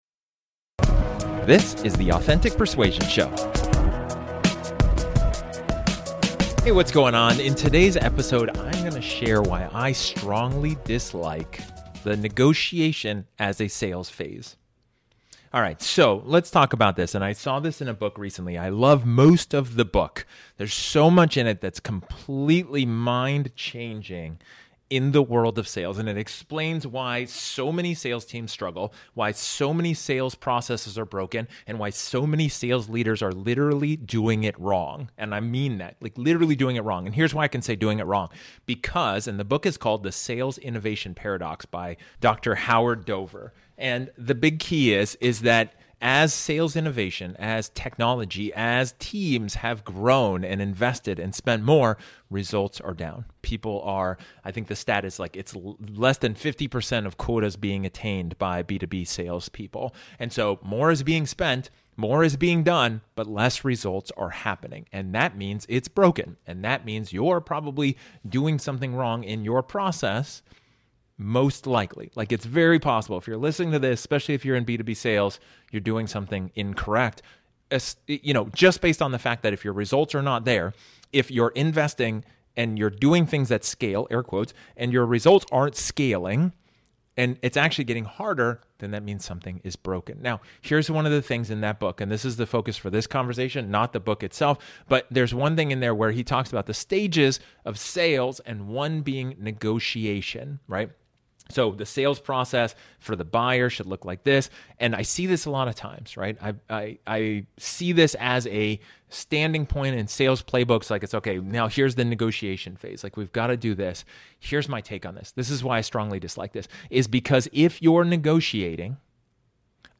In this solo episode, I talk about why you should stop negotiating. If you're asking for the sale and anticipating negotiation you're doing something incorrect.